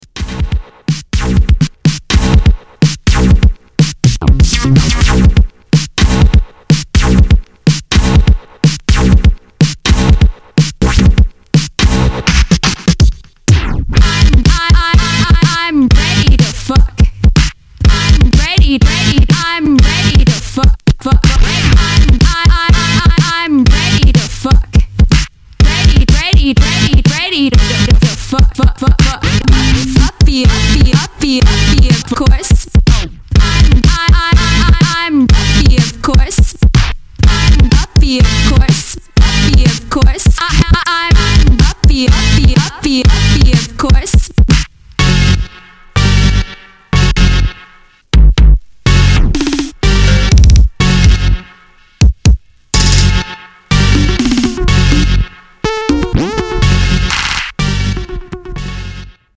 Electro Rap